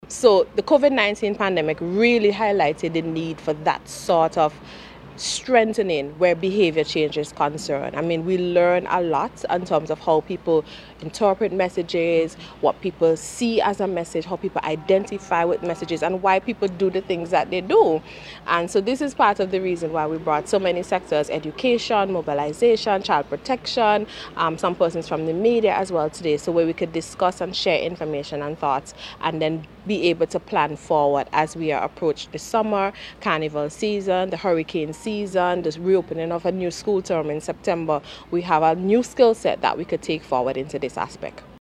In an interview with the Agency for Public Information